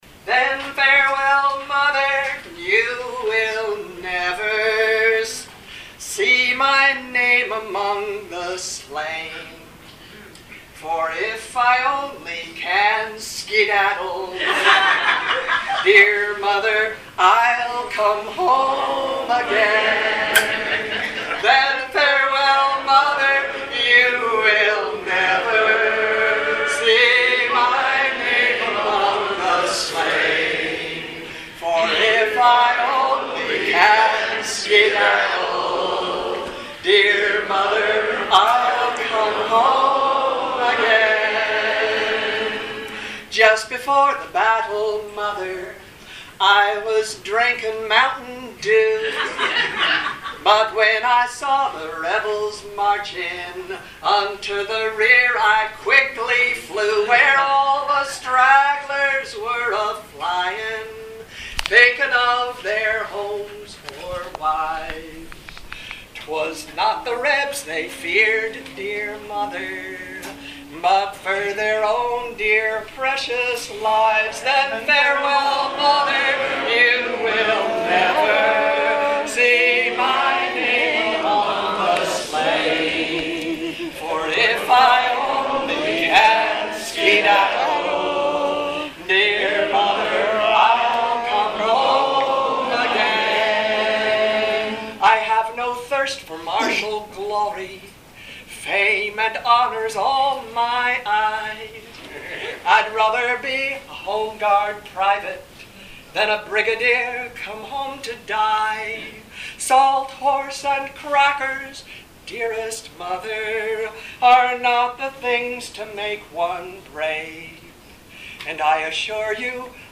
Though more than half the songes were recorded at the campers concert held at the end of the week, some were recorded at informal late night singing sessions.